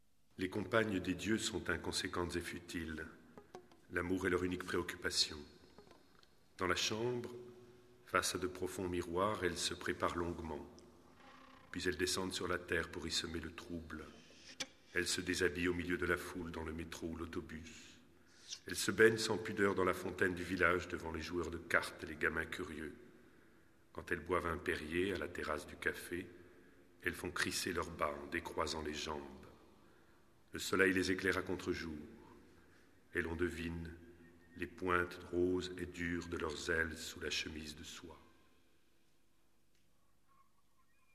live in Carpi
tastiere, samples, chitarra, tin whistle
voce recitante
flauti e sax soprano
percussioni